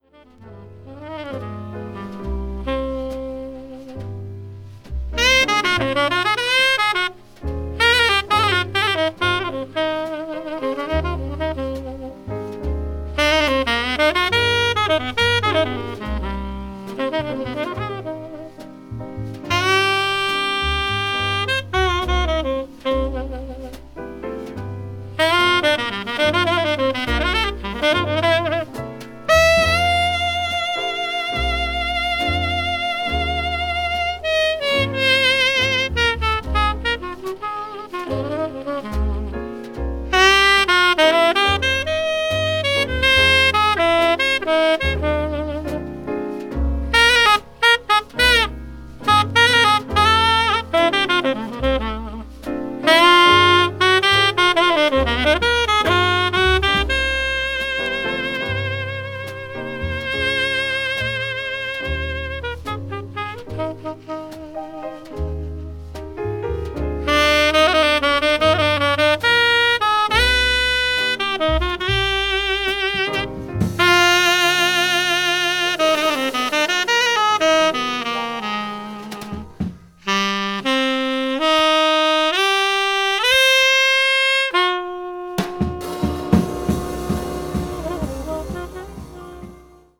media : EX/EX(some slightly noises.)
hard bop   modern jazz   mood jazz